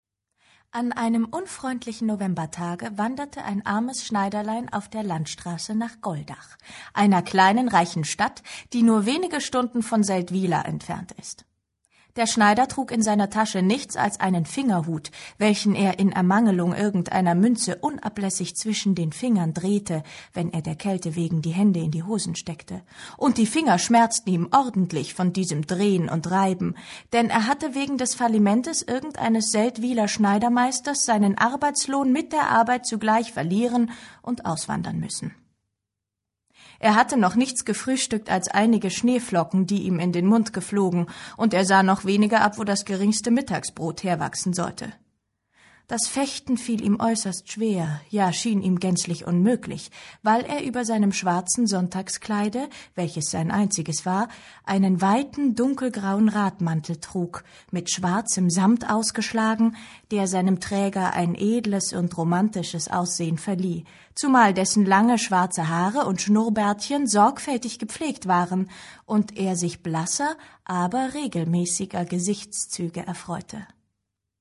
liest die Geschichte gefühl- und humorvoll.